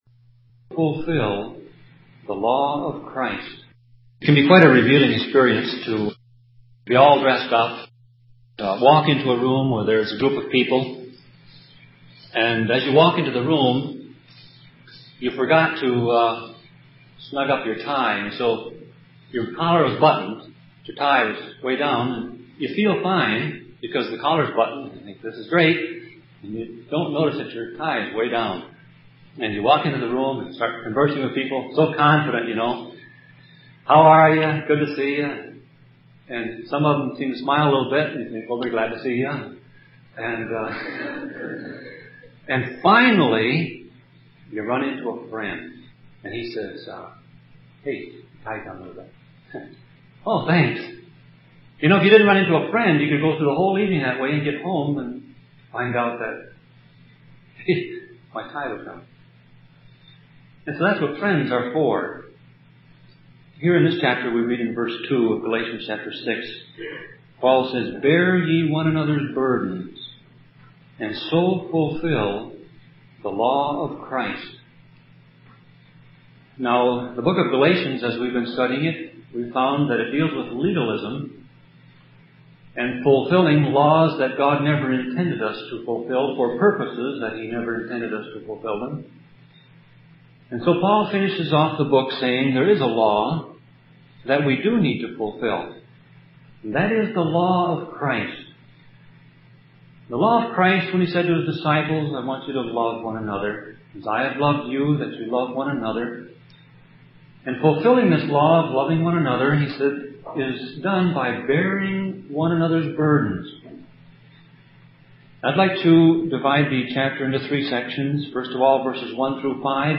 Sermon Audio Passage: Galatians 6 Service Type